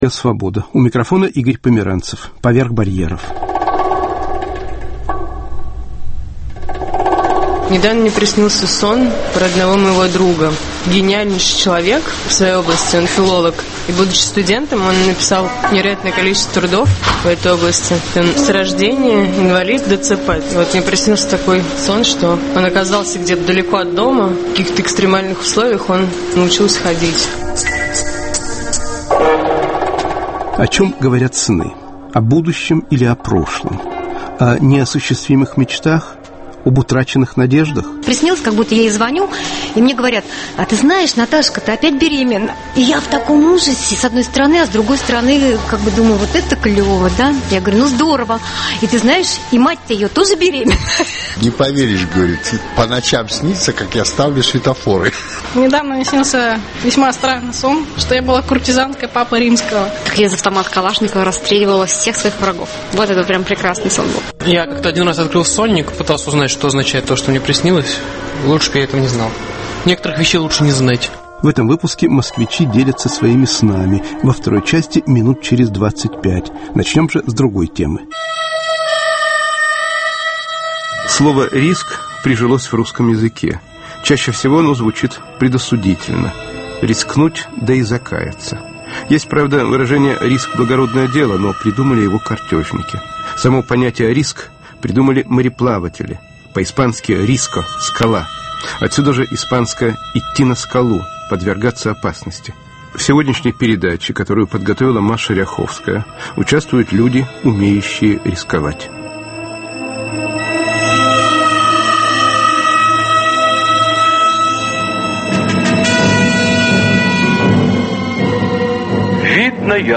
"Чувство риска". Передача с участием ветерана войны, альпиниста, актёра и др.